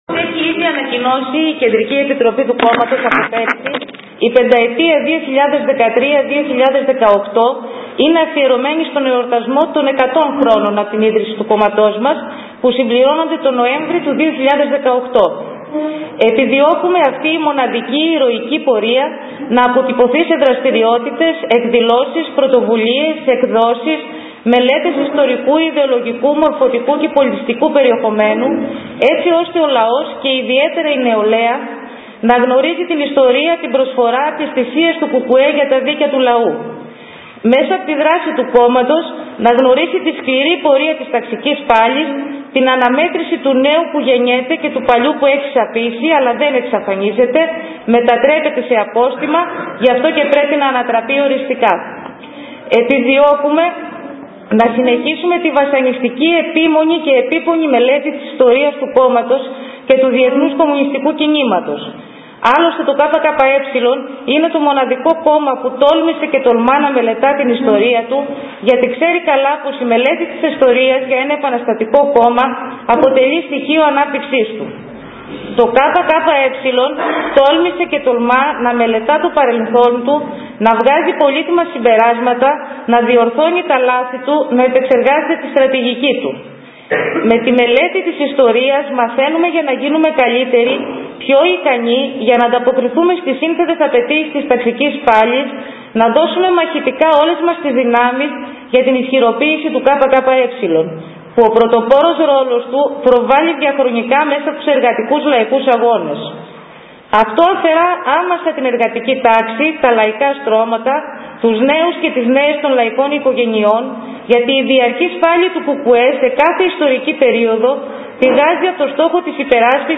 Πολιτική εκδήλωση με ομιλία και συζήτηση από το ΚΚΕ στην αίθουσα του Εργατικού Κέντρου.